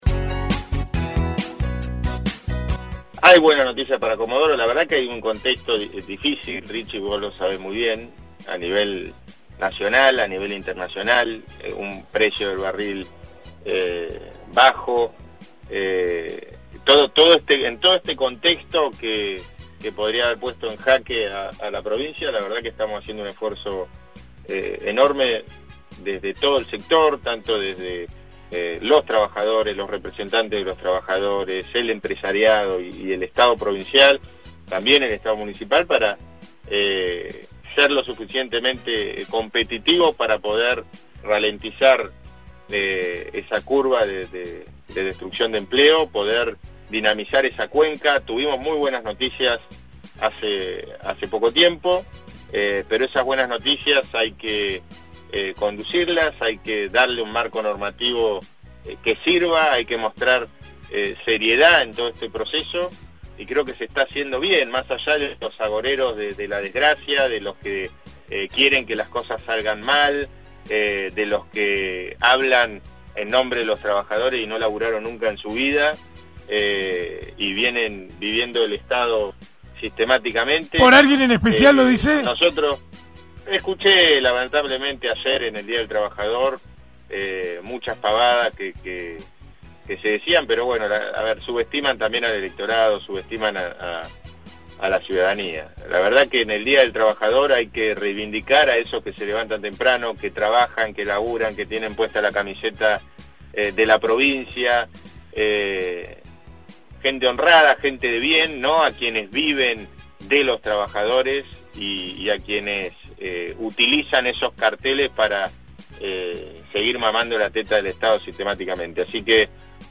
En el aire de LA MAÑANA DE HOY, el gobernador prometió buenas noticias para Comodoro y dejó su mensaje por el Día del Trabajador: